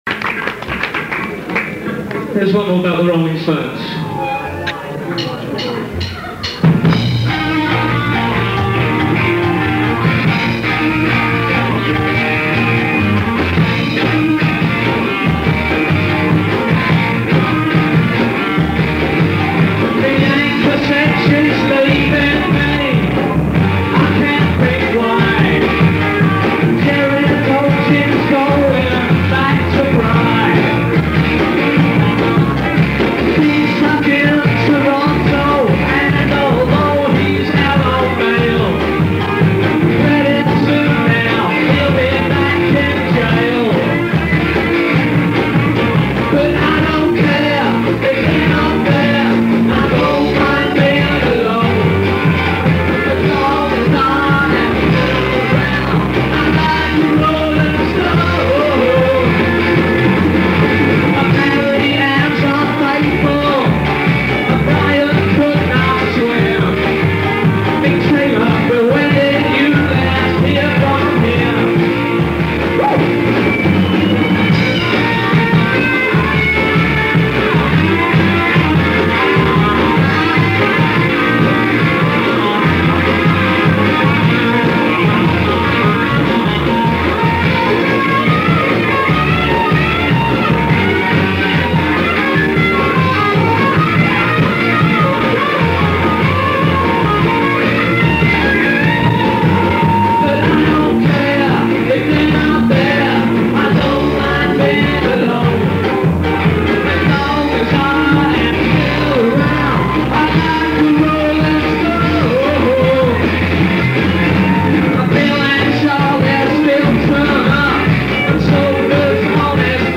The Brecknock, Camden Rd,